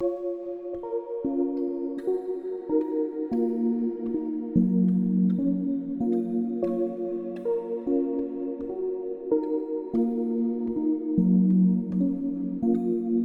Restless_Piano.wav